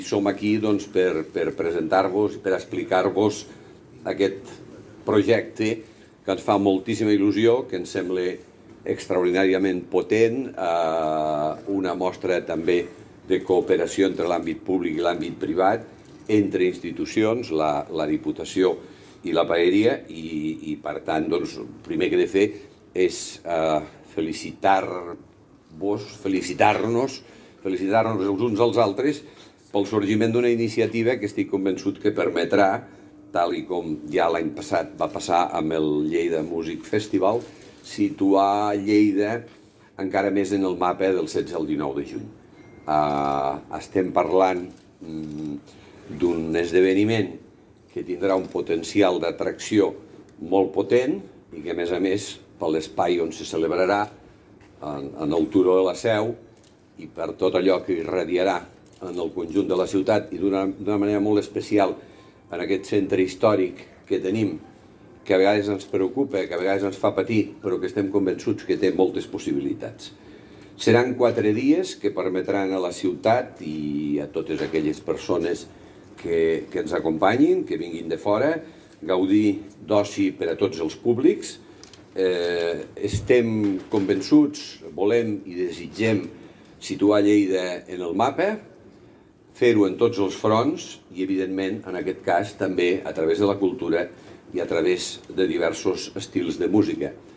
tall-de-veu-de-lalcalde-de-lleida-miquel-pueyo-sobre-el-nou-magnific-fest-lleida